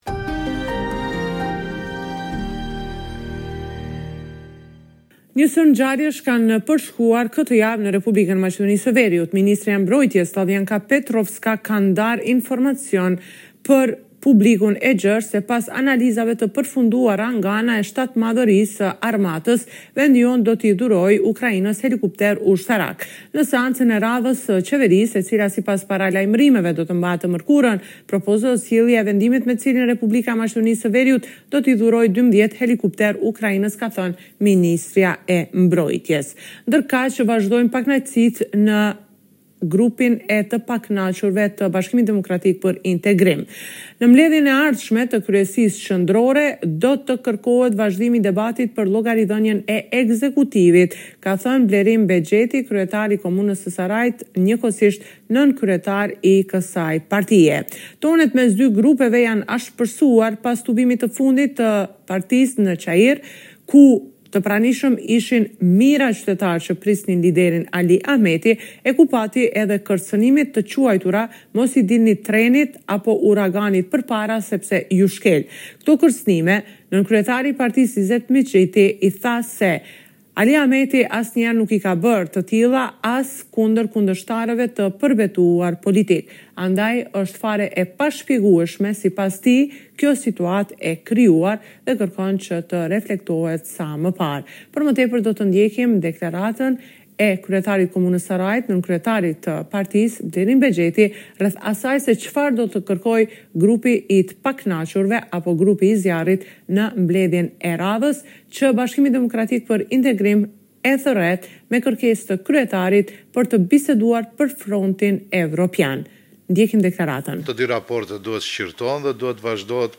Raporti me të rejat më të fundit nga Maqedonia e Veriut.